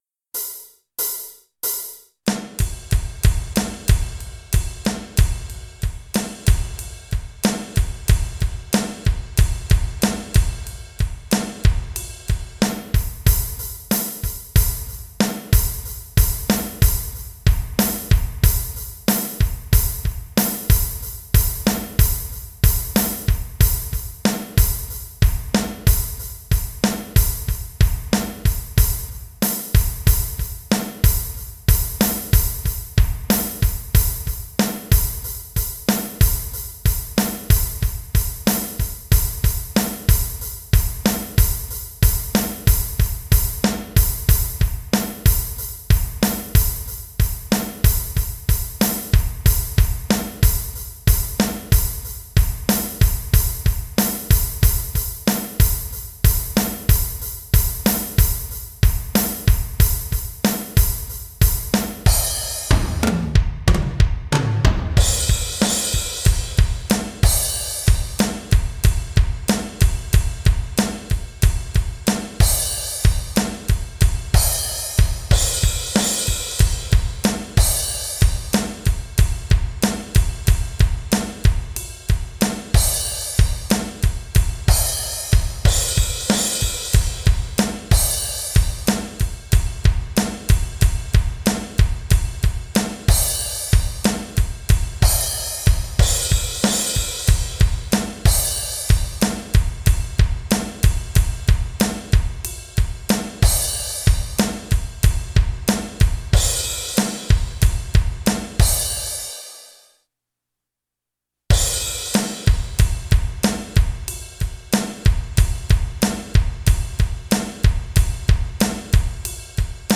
music_smorgasbord_chastitybelt_drums.mp3